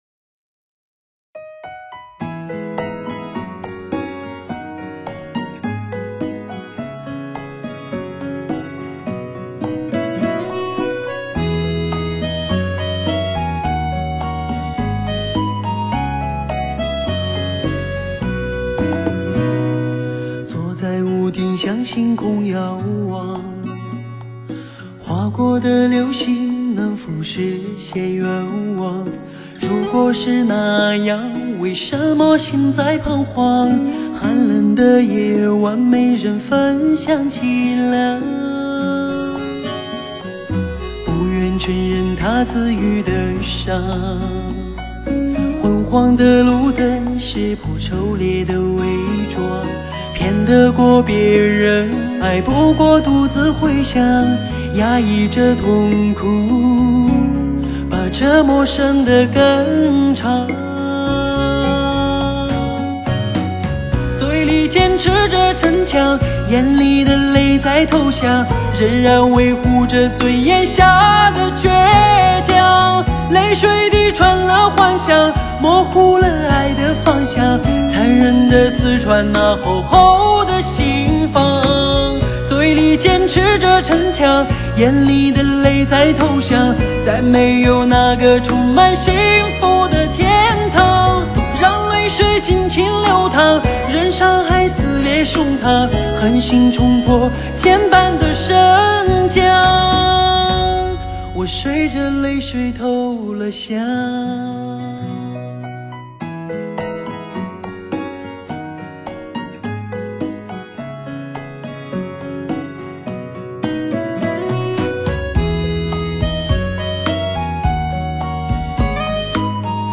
当红伤感歌曲精心收录